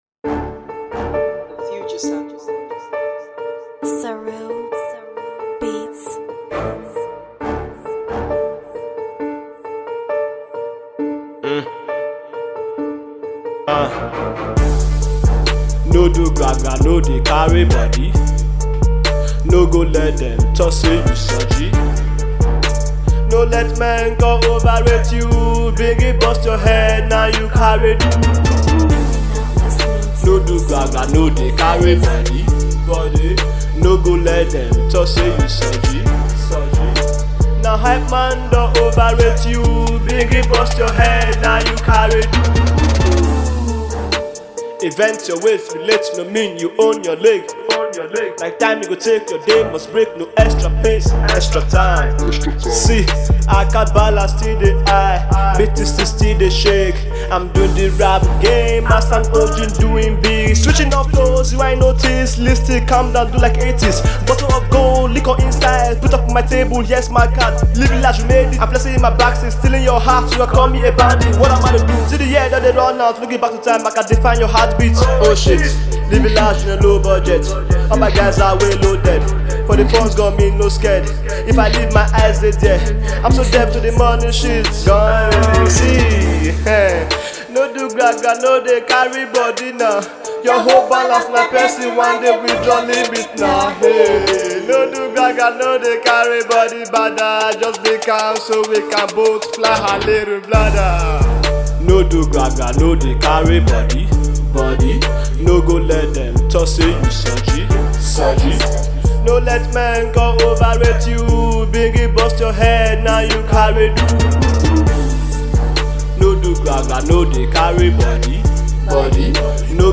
melodic vibes